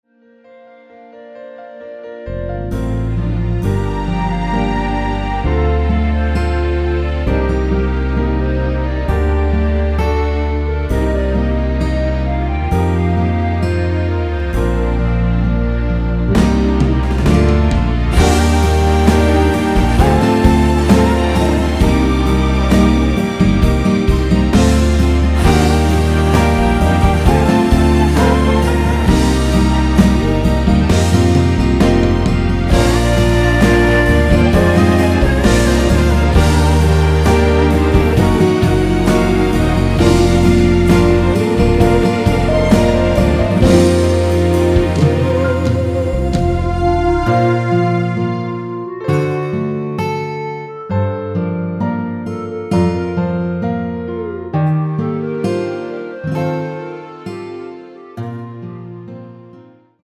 간주 코러스 외 다른 부분은 코러스 제작이 불가능 합니다.
원키 간주부분 코러스 추가된 멜로디 MR 입니다.(미리듣기 참조)
Ab
앞부분30초, 뒷부분30초씩 편집해서 올려 드리고 있습니다.
(멜로디 MR)은 가이드 멜로디가 포함된 MR 입니다.